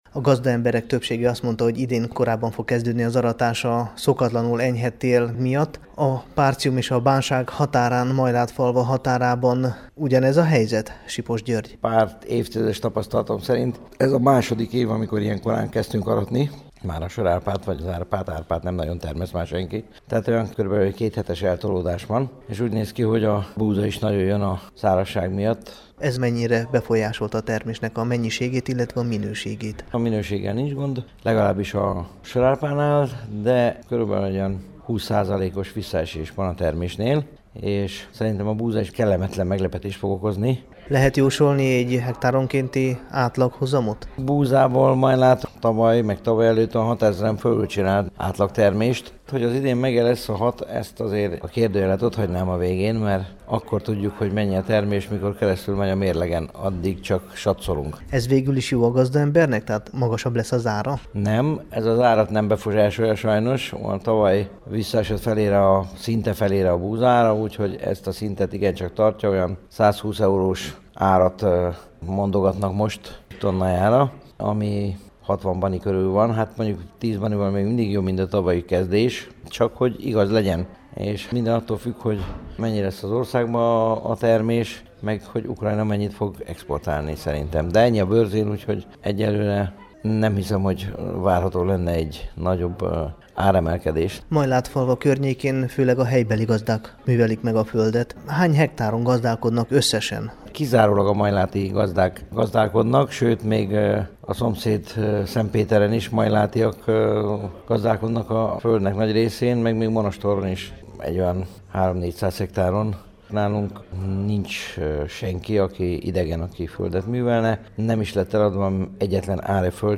Hallgassa meg a Temesvári Rádió számára készült beszélgetést!